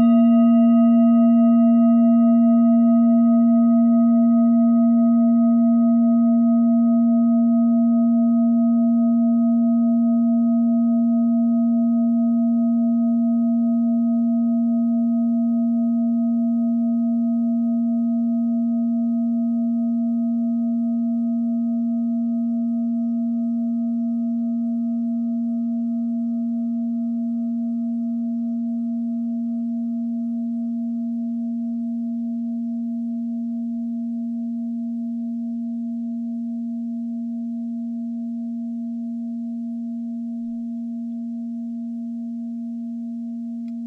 Klangschale Bengalen Nr.2
Klangschale-Gewicht: 1320g
Klangschale-Durchmesser: 20,9cm
Die Klangschale kommt aus einer Schmiede in Bengalen (Ostindien). Sie ist neu und wurde gezielt nach altem 7-Metalle-Rezept in Handarbeit gezogen und gehämmert.
(Ermittelt mit dem Filzklöppel oder Gummikernschlegel)
Die Frequenz des Hopitons liegt bei 164,8 Hz und dessen tieferen und höheren Oktaven. In unserer Tonleiter liegt sie beim "E".